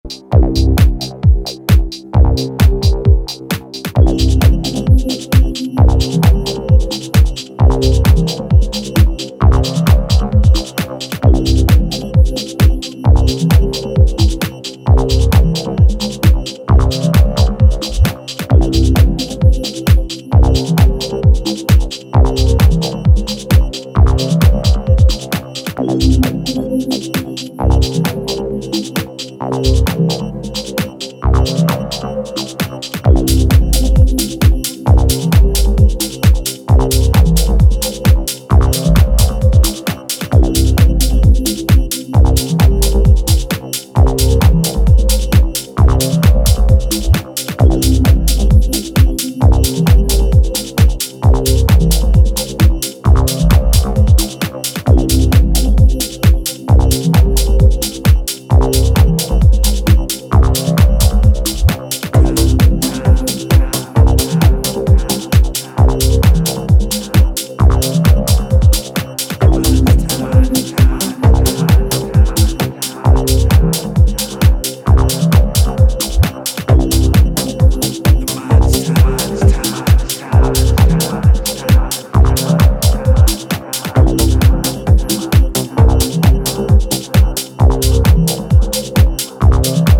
ダーティーなアシッドベースとハイトーンのシンセラインが不穏に対比する